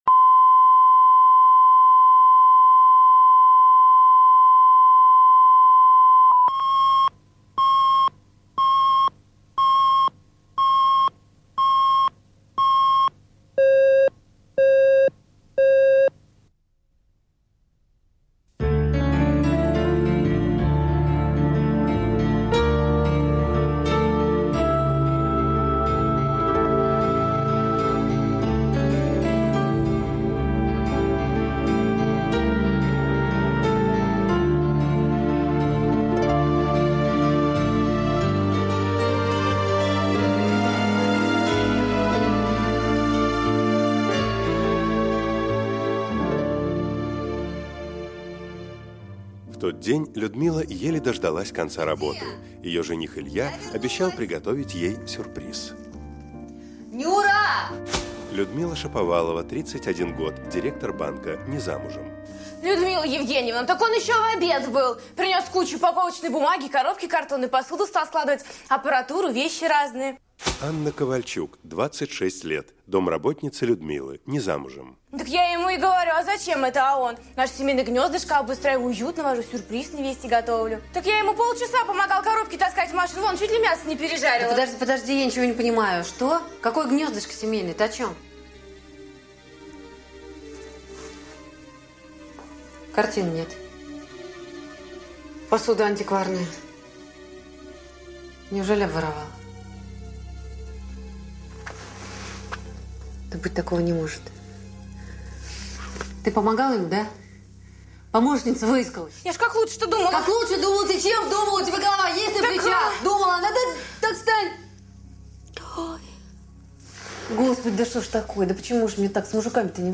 Аудиокнига Кусочек звезды | Библиотека аудиокниг